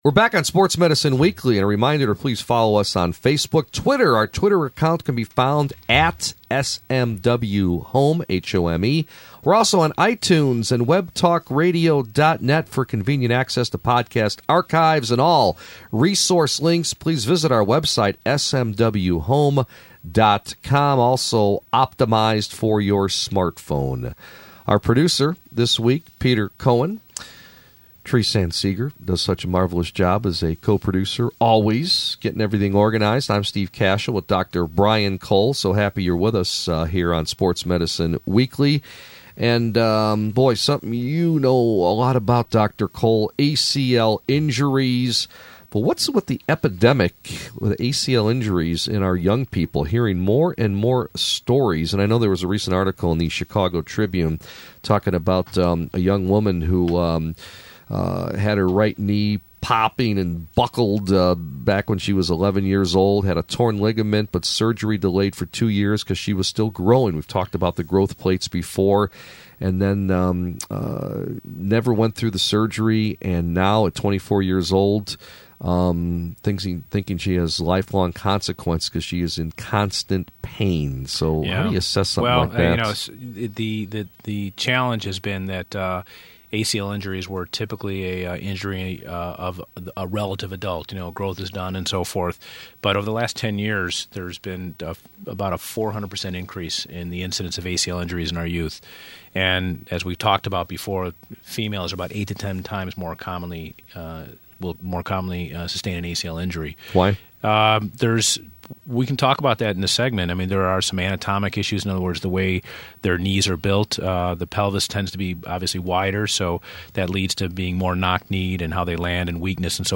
I was recently asked by ESPN’s Sports Medicine Weekly radio show to do an interview related to a recent article in the Chicago Tribune covering the recent increase in anterior cruciate ligament (ACL) tears.